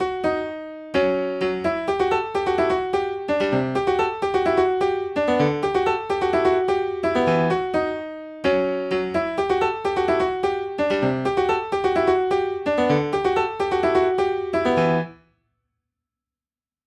元のアルペジオ系のMIDIデータから編集したフレーズパターン全体を反転（リバース）します。
一瞬なんだこりゃ・・・と思いきや、一部「おっ・・・！」と思えるパターンがあれば、それは美味しいフレーズ部分です。